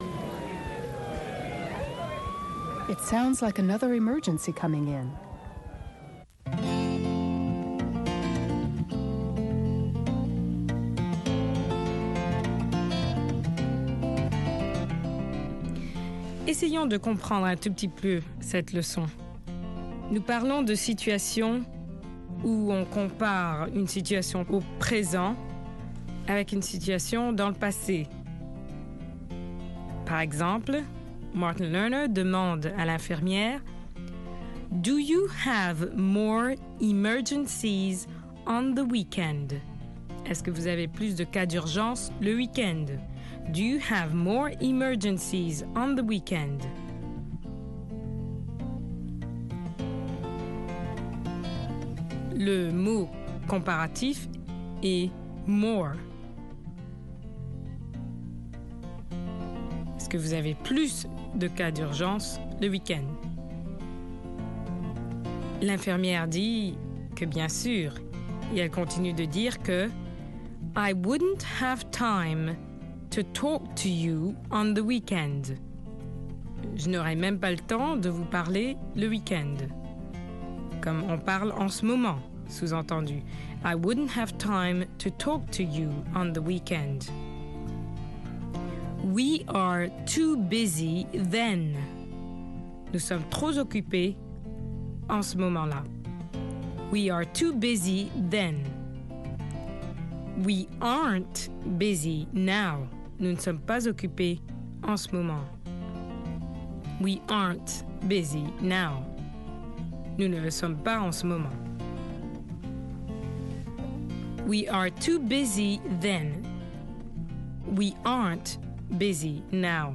Des conversations en anglais américain par des Africains, qui ont trait à la vie quotidienne au Sénégal.